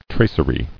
[trac·er·y]